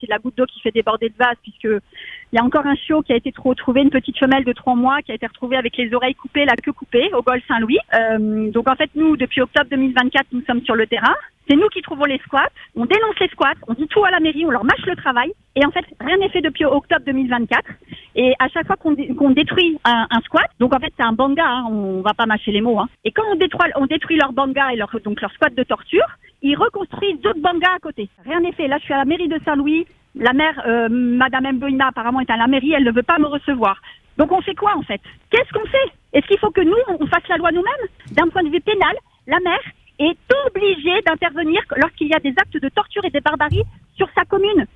On vous en parlait sur Free Dom.